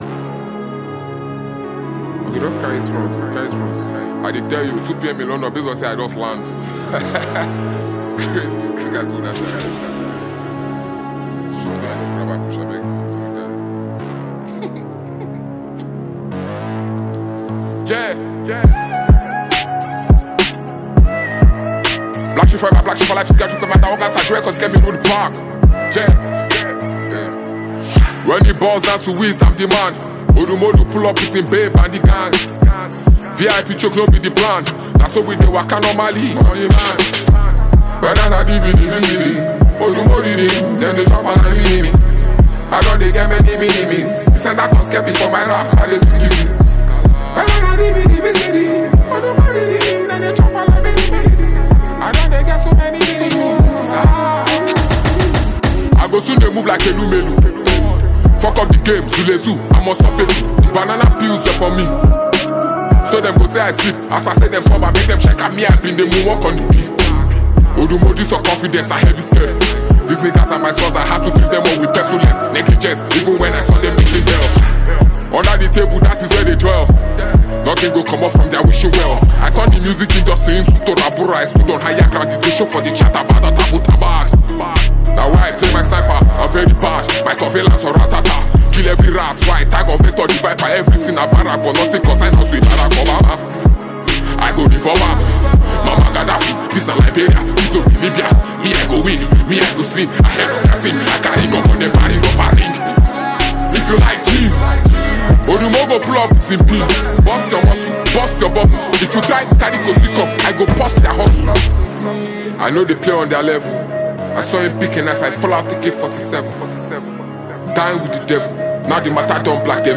energetic and vibrant vocals